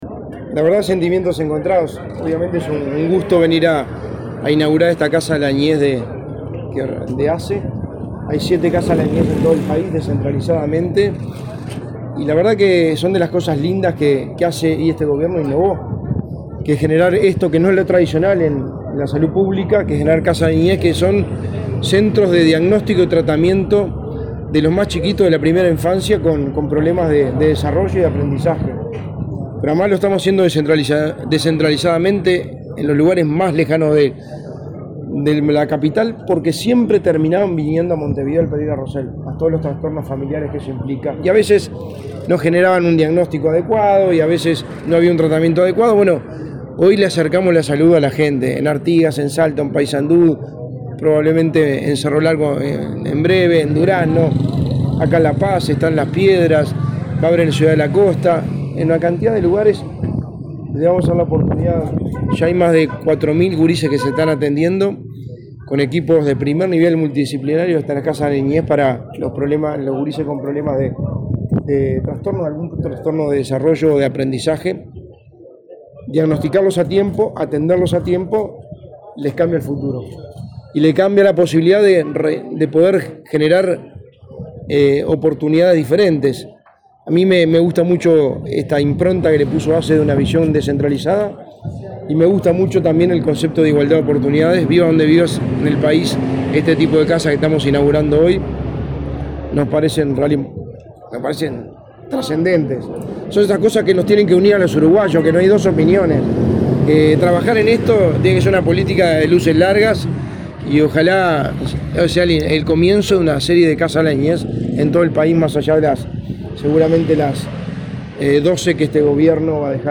Declaraciones del secretario de Presidencia, Álvaro Delgado
Luego Delgado dialogó con la prensa.